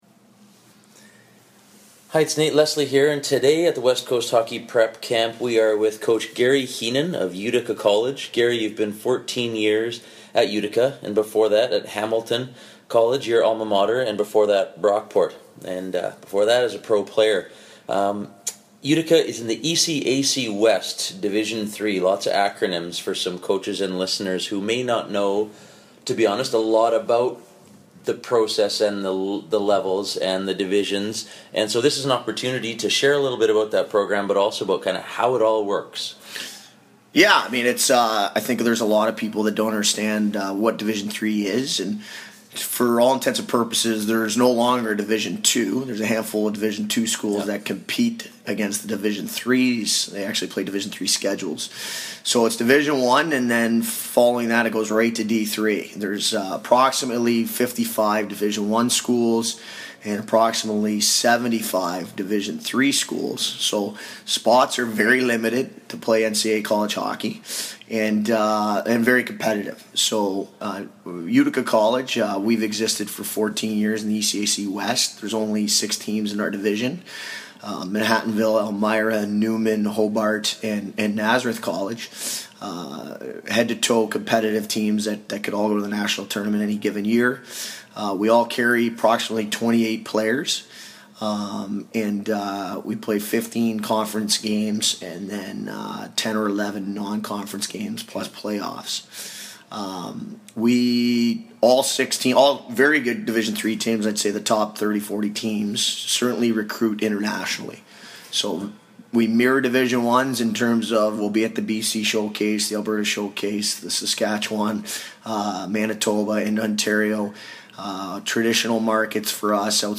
In-Depth Interview
(Excuse the sounds of the rink in the background, it can be hard to escape the buzz of camp!)